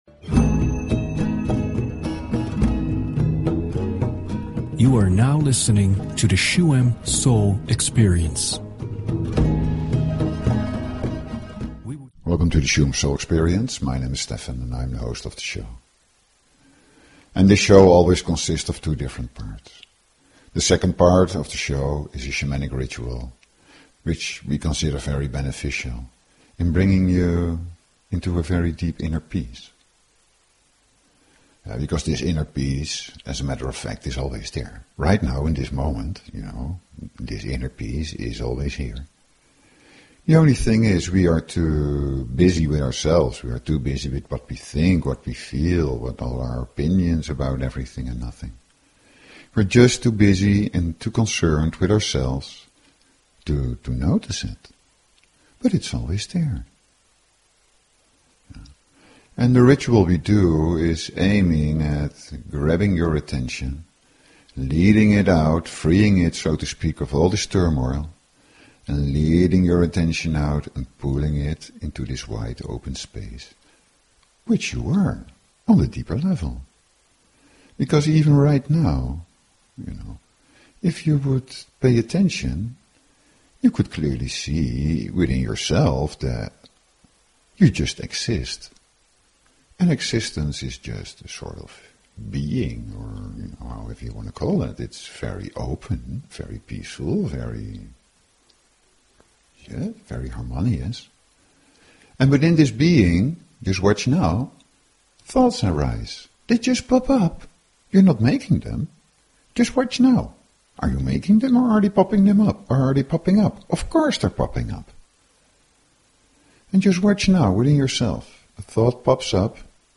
Talk Show Episode, Audio Podcast, Shuem_Soul_Experience and Courtesy of BBS Radio on , show guests , about , categorized as
To help you to let go of negativity, heavy energy and inner turmoil Shuem Soul Experience offers a Shamanic Meditation ritual in the second part of the show.